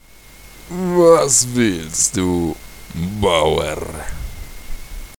Deutsche Sprecher (m)
Ich spreche ausschlie�lich wie ein m�nnlicher Khajiit in Skyrim. Nat�rlich in verschiedenen Variationen: selbstbewusster Assassine, einfacher Dieb, symphatischer NPC oder auch ein gew�hnlicher Khajiit-Karawanen-H�ndler.